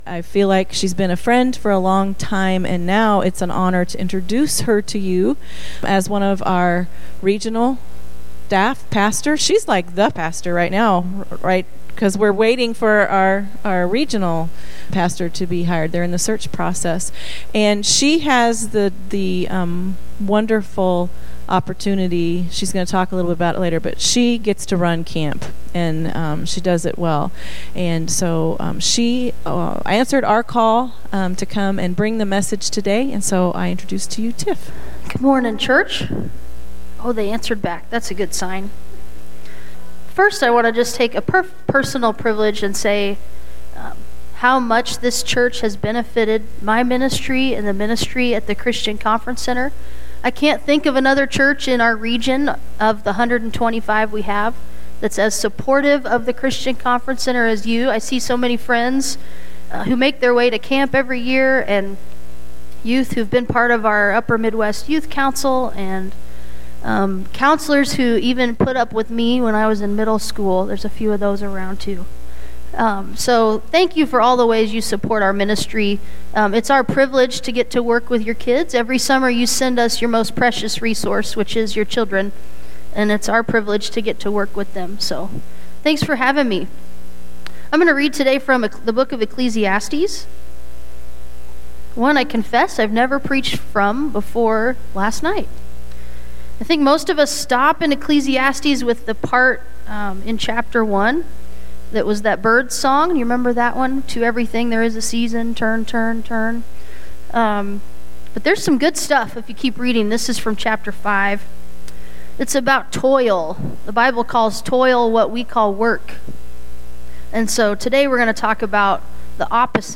Guest Pastor